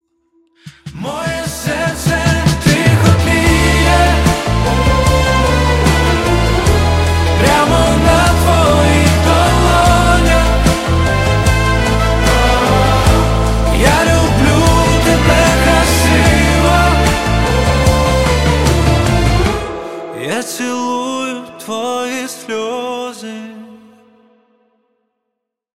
Українська музика на дзвінок 2026.